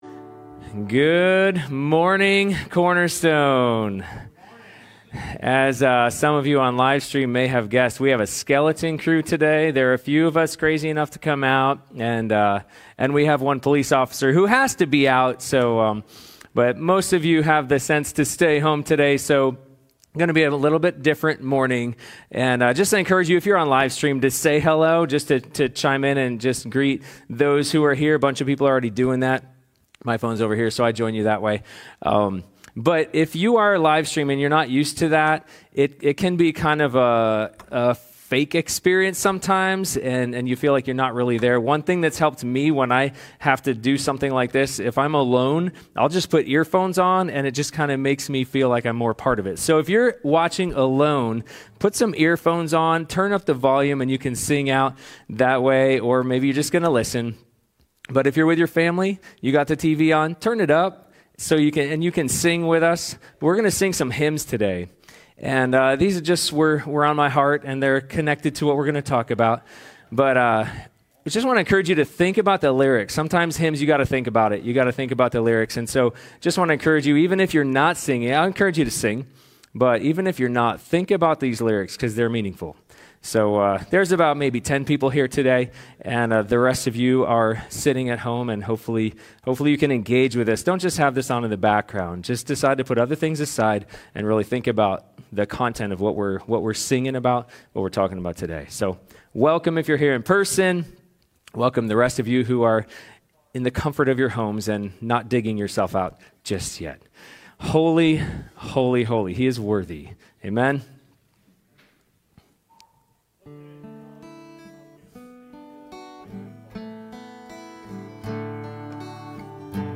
John 17:1-5 Service Type: Sunday Morning Youversion Event How can I bring glory to God at my workplace?